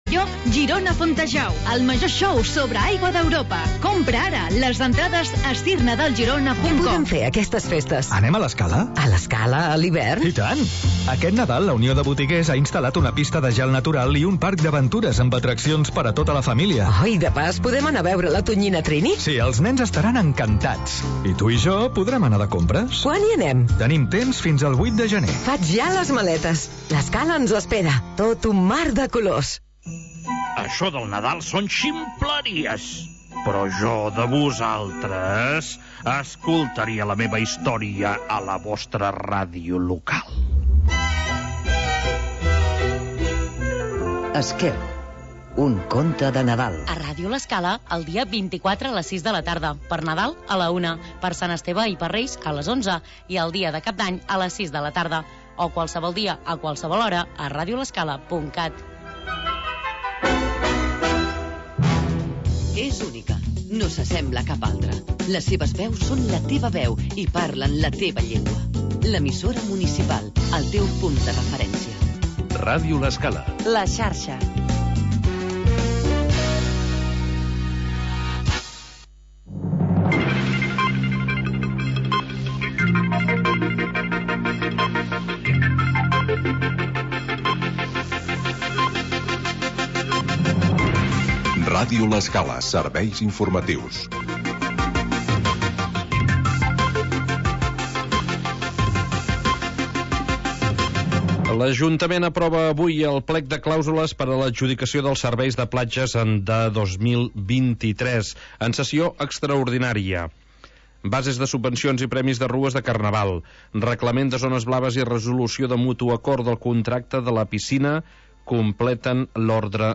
Noticiari d'informació local